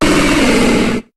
Cri de Cerfrousse dans Pokémon HOME.